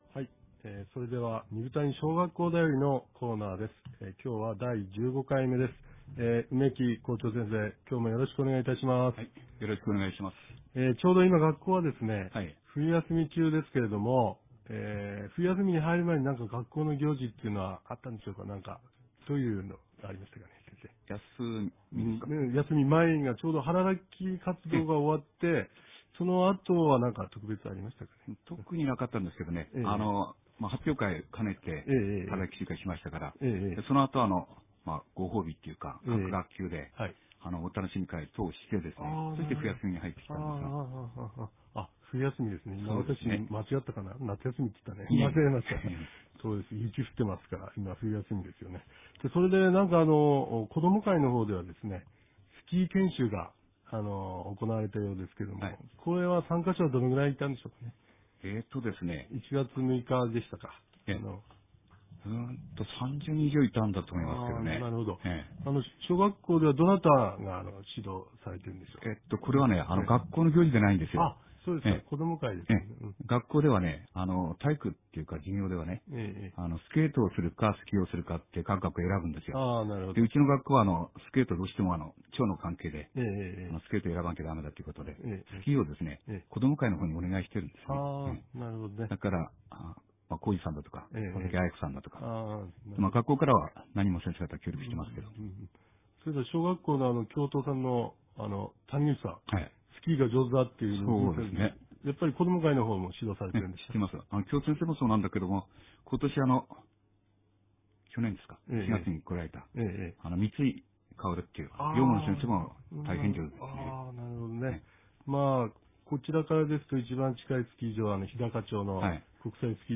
インタビューコーナー